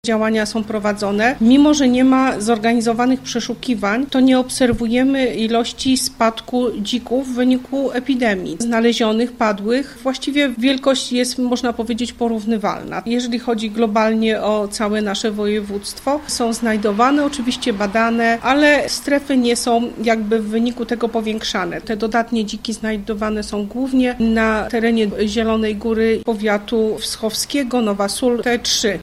– Do końca kwietnia odstrzelonych zostało około 3,5 tysiąca dzików – informuje Zofia Batorczak, Wojewódzki Inspektor Weterynarii.